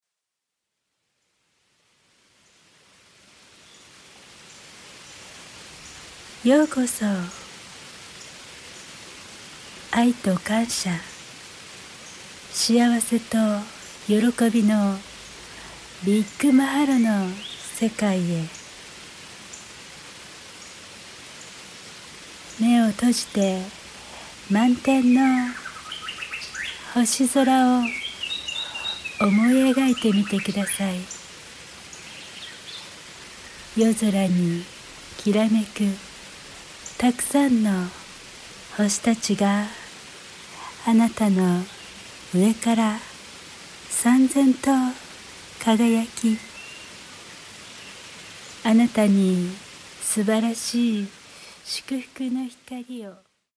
柔らかい声で「さあ、息を吐きましょう」というように潜在意識に呼び掛けてくれるので、